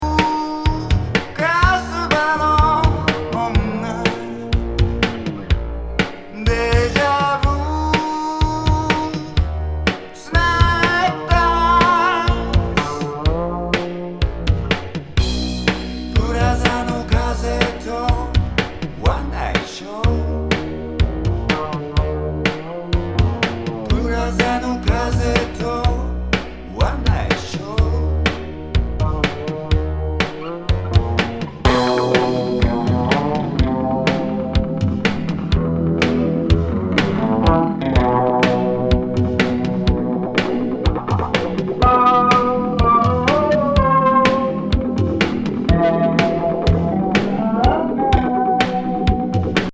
フェイキー・グルーブ